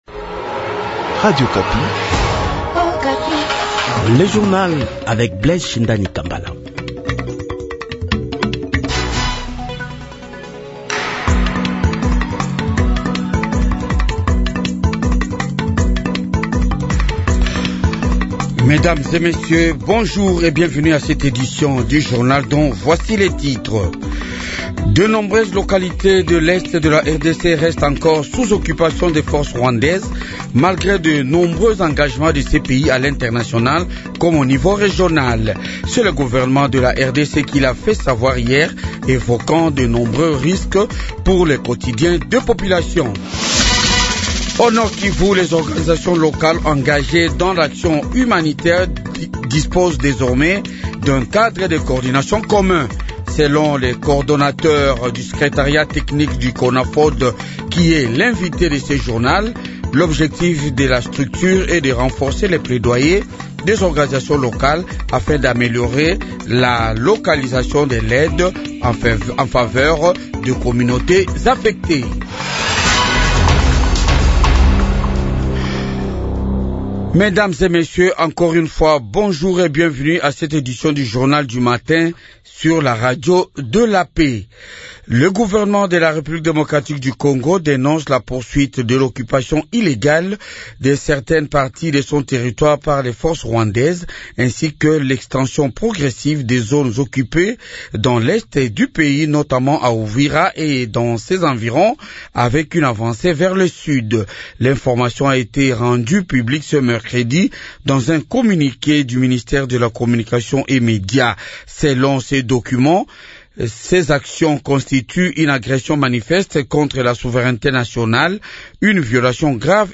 journal du matin 7h